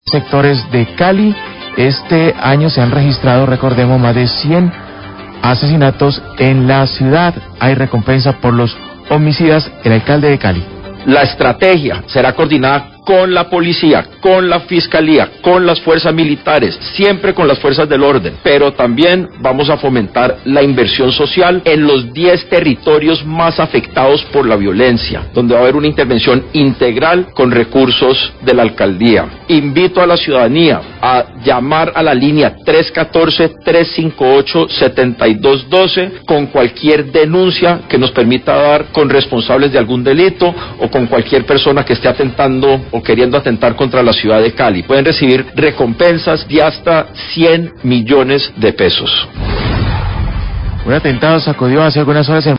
Alcalde Eder habla respecto a alarmante situación de seguridad en la Ciudad
Radio